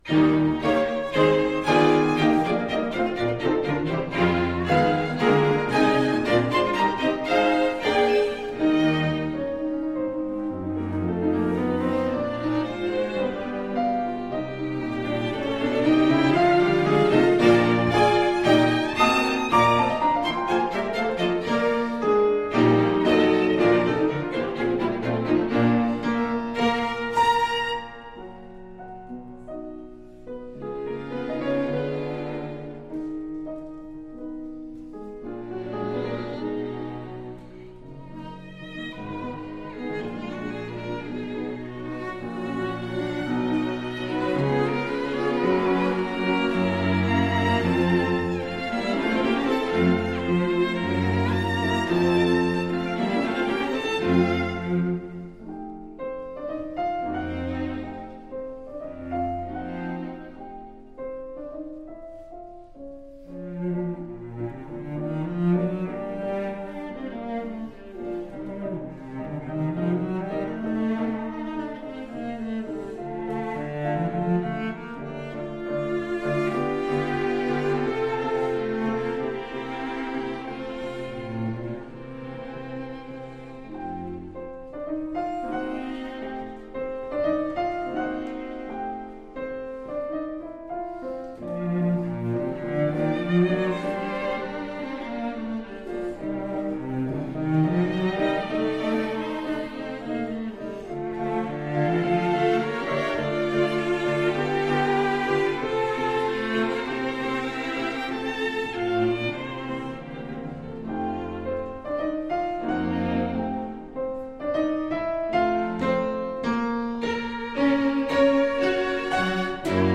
Piano Quintet
Violin 1 Violin 2 Viola Cello
Style: Classical
Audio: Boston - Isabella Stewart Gardner Museum
Audio: Musicians from Ravinia's Steans Music Institute